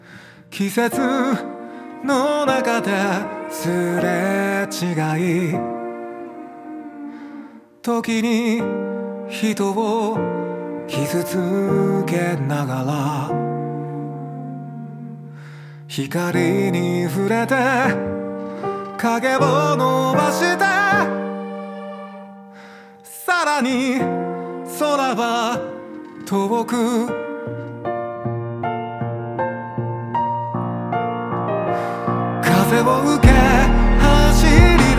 Жанр: J-pop / Поп